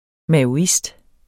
Udtale [ mæoˈisd ]